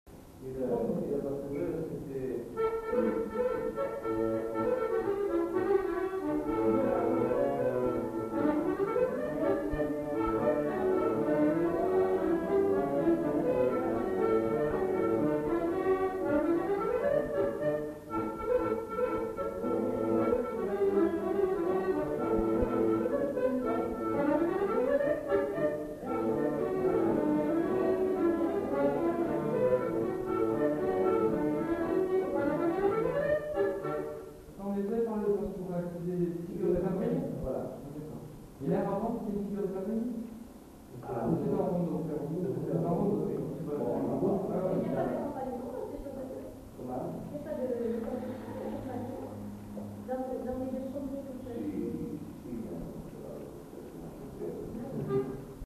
Chants et airs à danser
enquêtes sonores